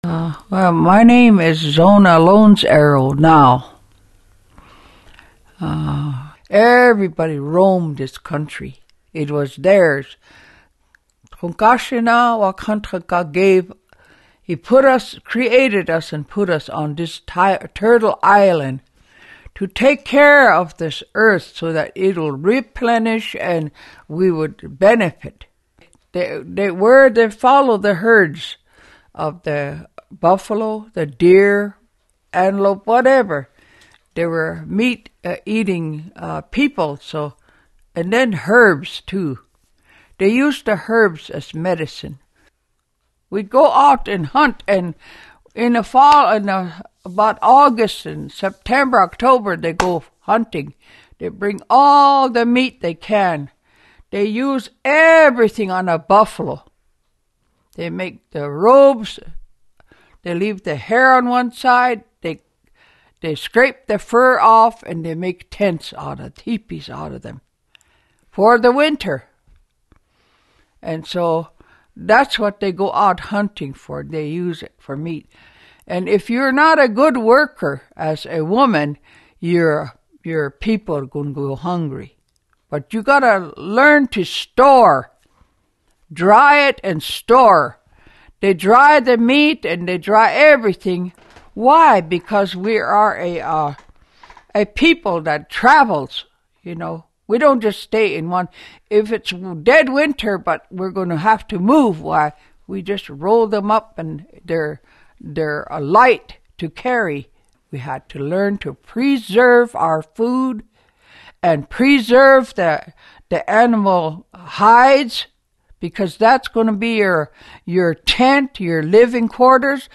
Turtle Island Storyteller